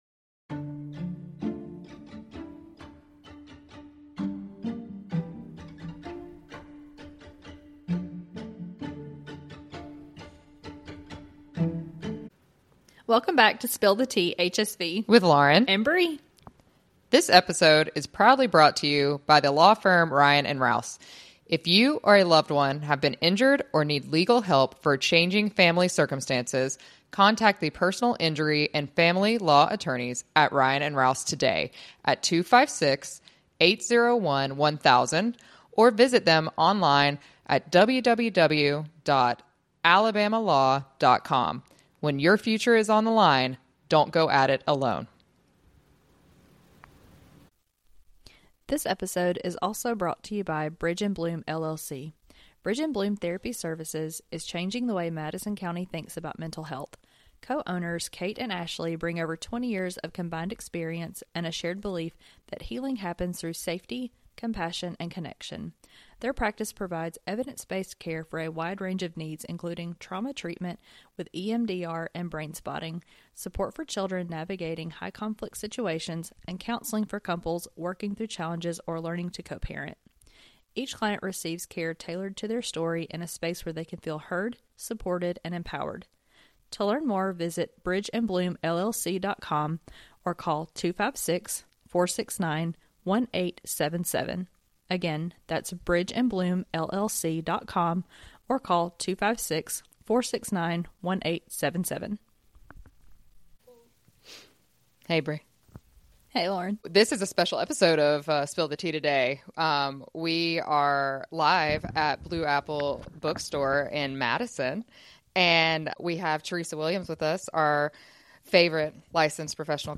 Together, they engage in an honest and heartfelt discussion on navigating the aftermath of betrayal and finding a path forward. A special thank-you to everyone who joined us in person and supported this live recording.